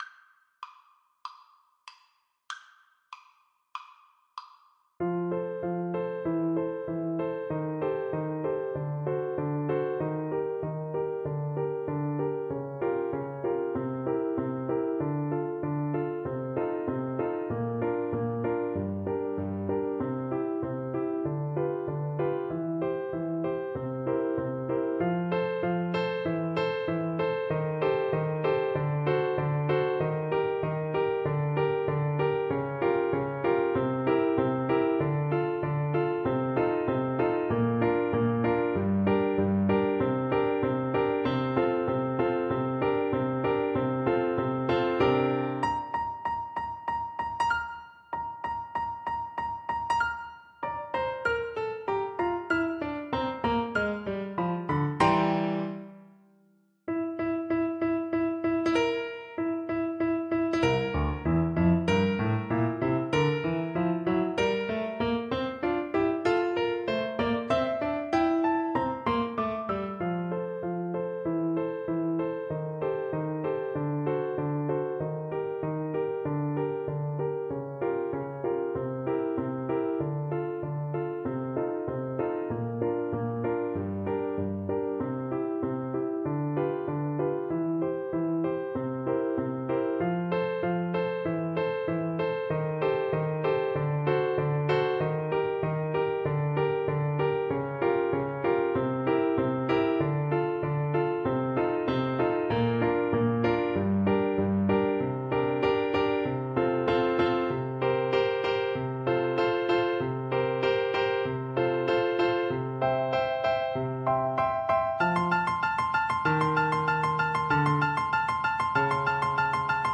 4/4 (View more 4/4 Music)
Molto Allegro = c. 160 (View more music marked Allegro)
Classical (View more Classical Flute Music)